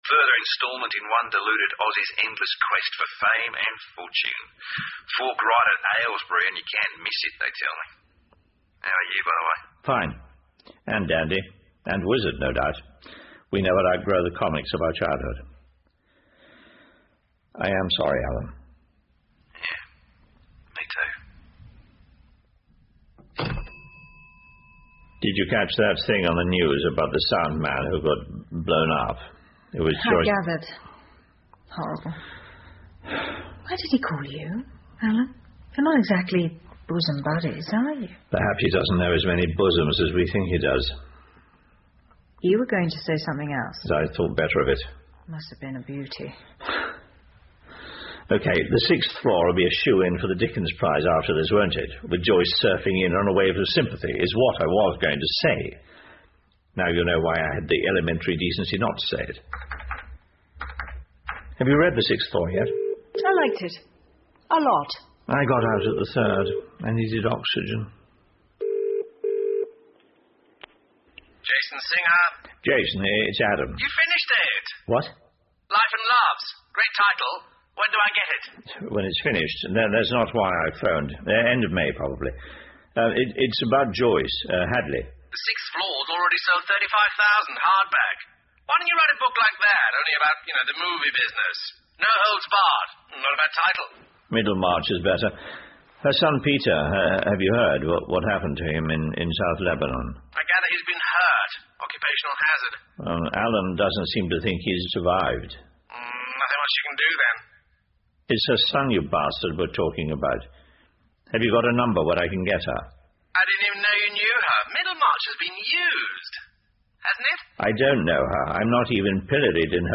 英文广播剧在线听 Fame and Fortune - 36 听力文件下载—在线英语听力室